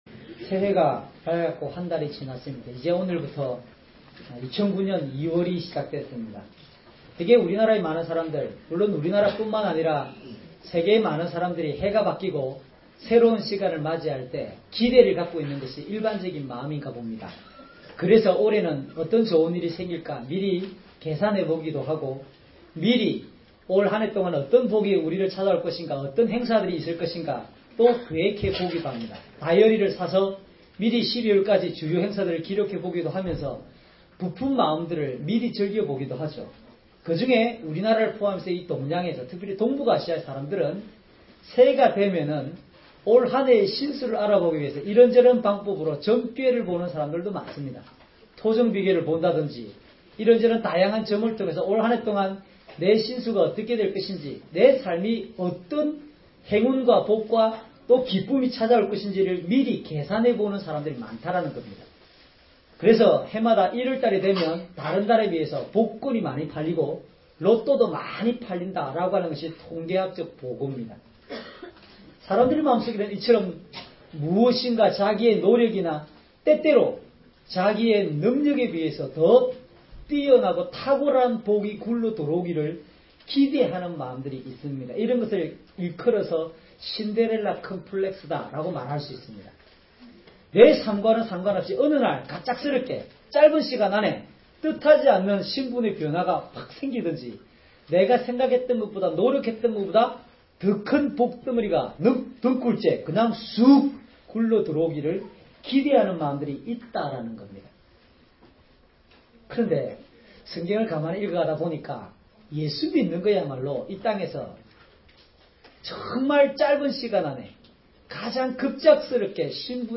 주일설교 - 09년 02월 01일 "파멸의 삶이 회복됩니다."